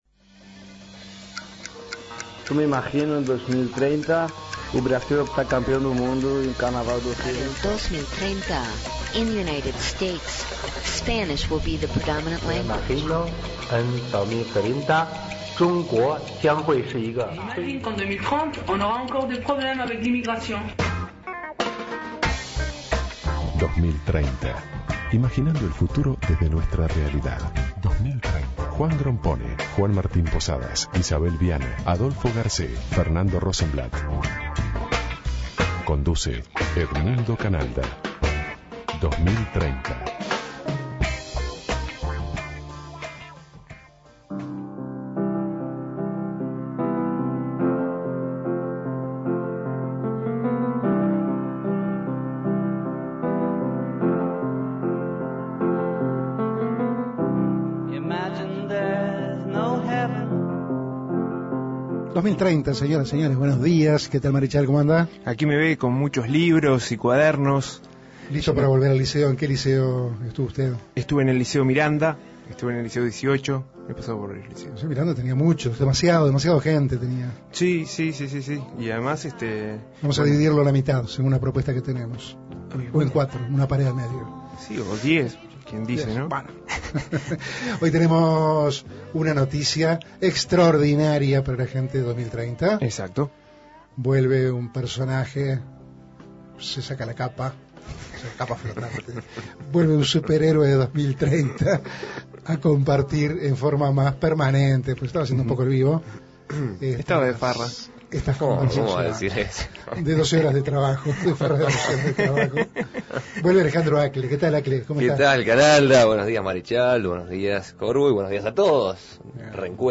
Invitado: Daniel Corbo, consejero del Codicen.